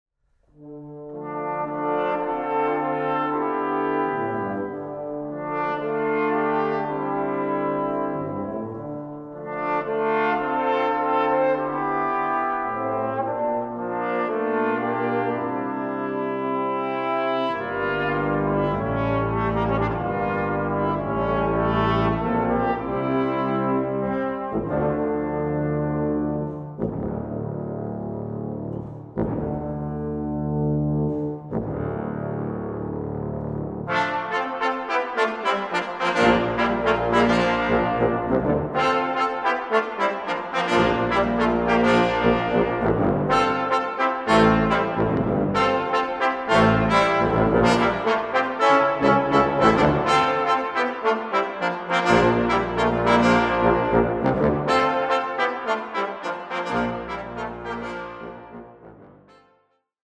Fantasia for brass ensemble